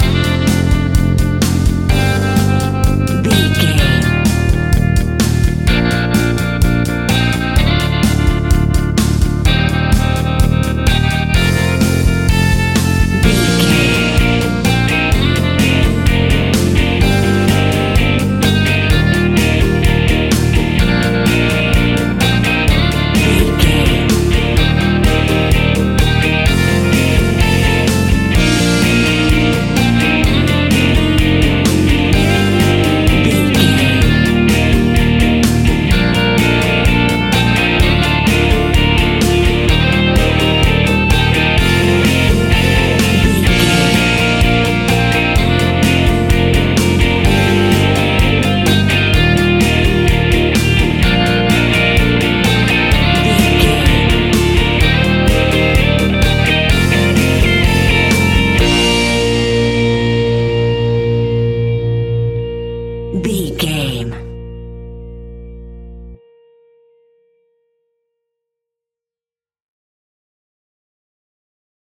Uplifting
Ionian/Major
B♭
energetic
cheesy
instrumentals
guitars
bass
drums
piano
organ